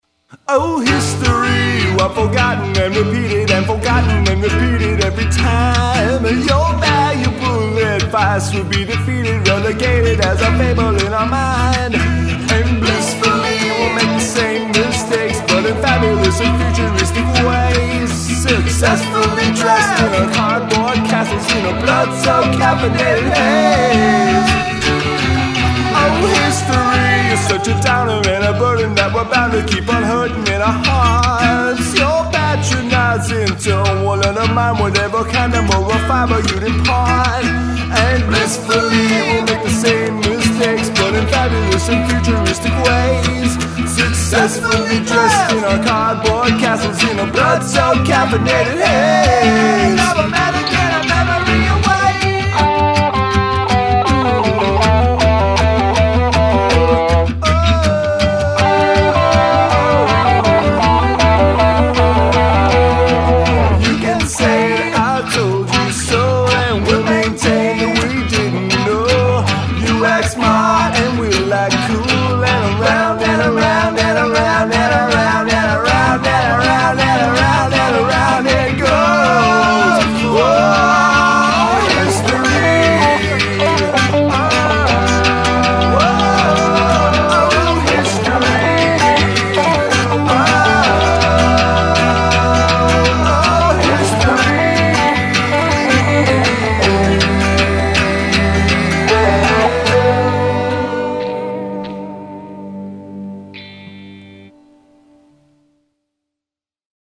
Guitar and Vox only (bass is prohibited)
It really sounds like it needs bass and drums.
There's some nice licks as well.